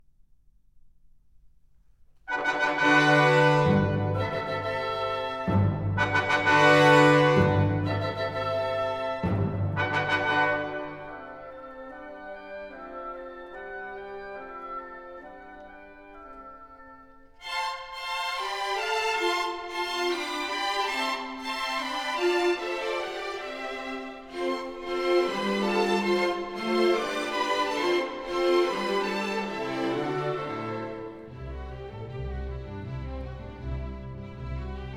# Classical Crossover